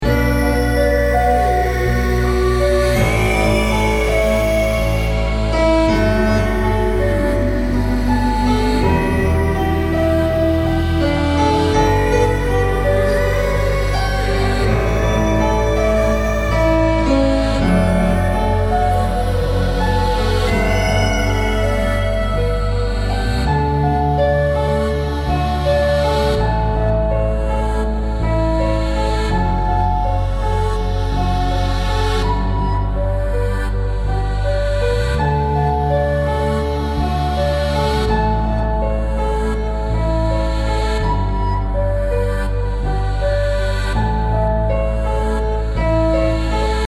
BPM 82